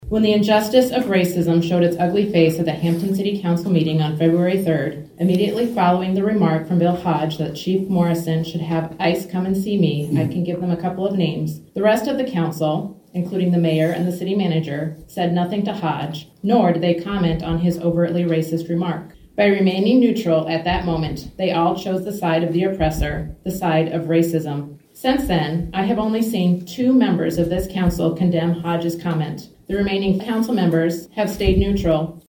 Monday night, during public comment of the Hampton City Council budget workshop meeting, some residents voiced their concerns about councilmember Bill Hodge and a remark he made during the February 3rd meeting about ICE making arrests in the area.